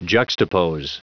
Prononciation du mot juxtapose en anglais (fichier audio)
Prononciation du mot : juxtapose